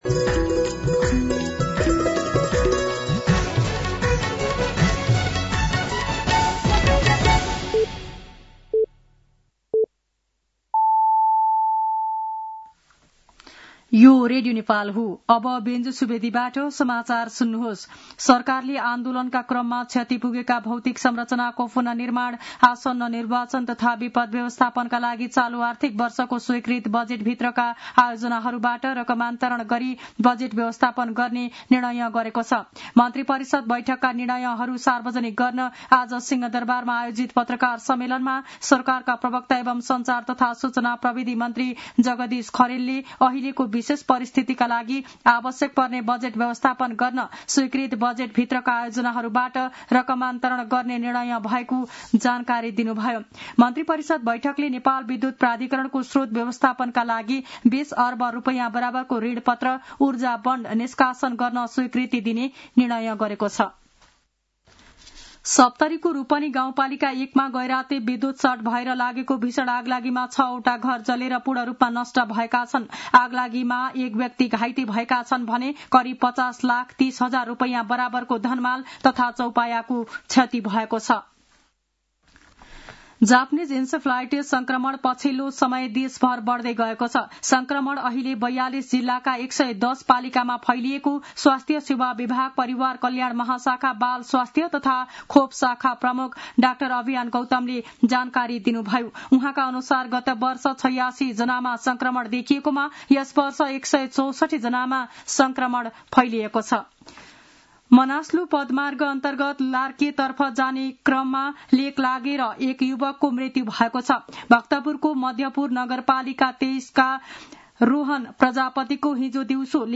An online outlet of Nepal's national radio broadcaster
साँझ ५ बजेको नेपाली समाचार : २४ असोज , २०८२